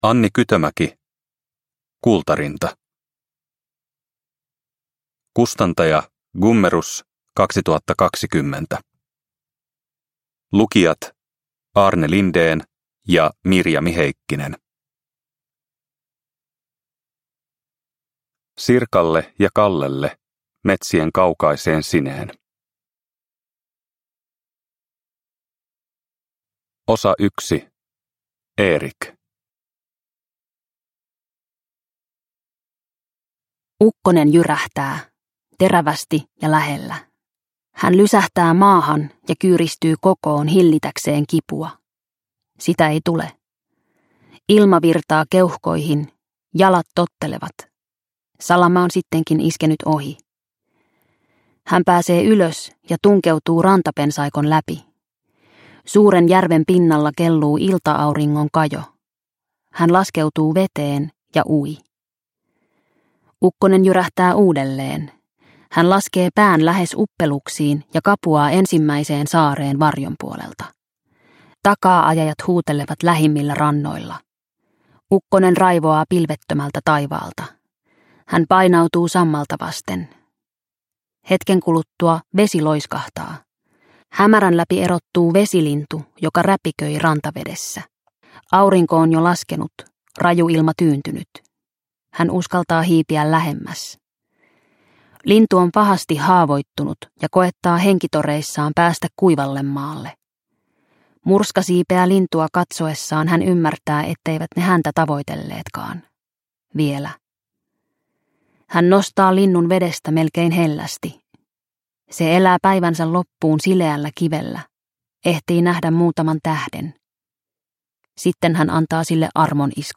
Kultarinta – Ljudbok – Laddas ner